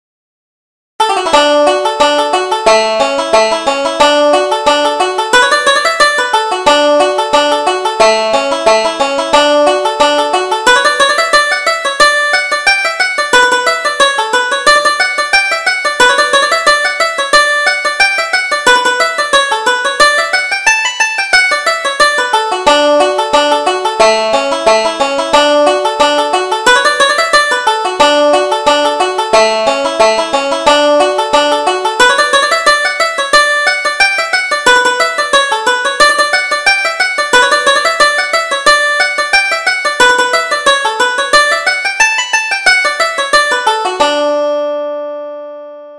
Reel: The Grey Daylight